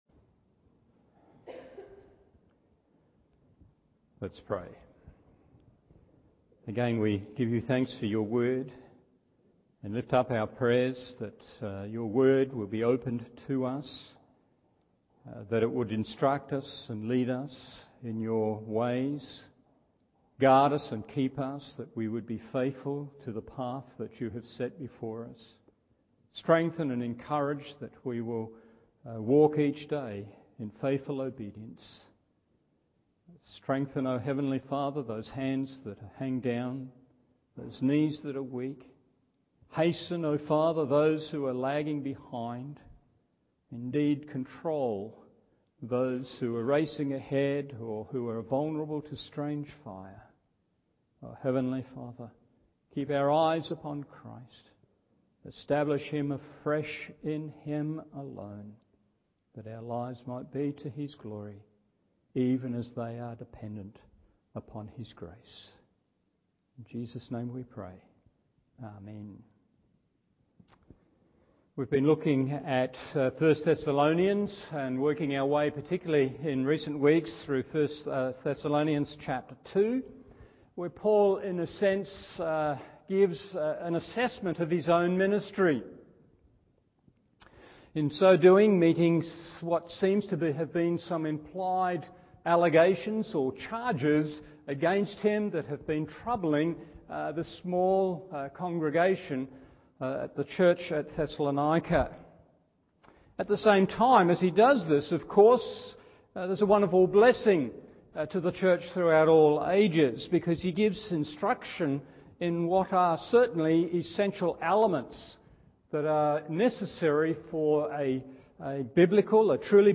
Evening Service 1 Thessalonians 2 1.